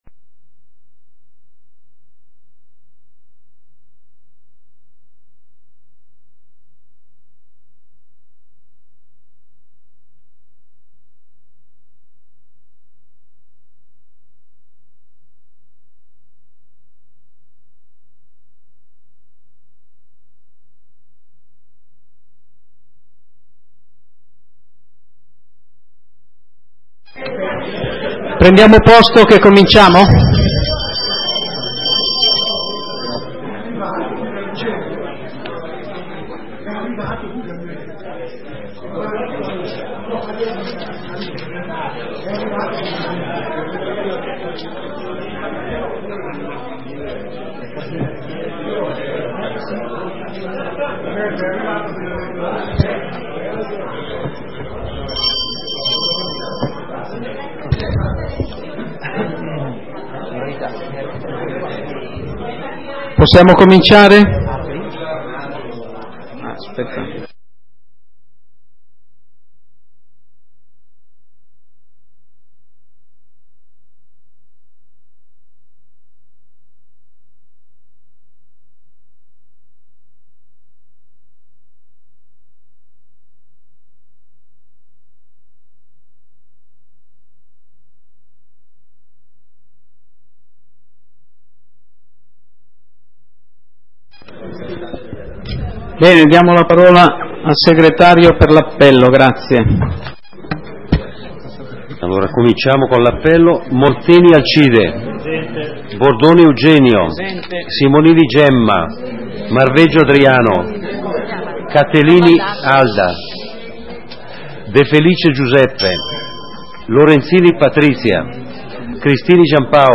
Seduta consiglio comunale del 25 novembre 2011 - Comune di Sondrio
Ordine del giorno ed audio della seduta consiliare del Comune di Sondrio effettuata nella data sotto indicata.